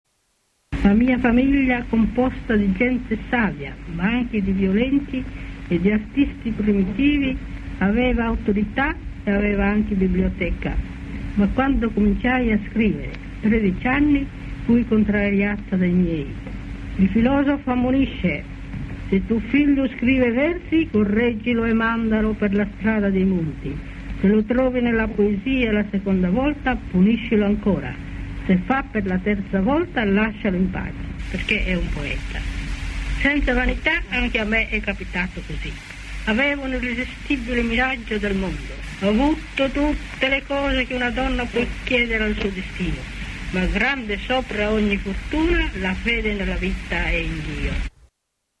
Discorso-di-Grazia-Deledda-al-conferimento-del-premio-Nobel-1926-1.mp3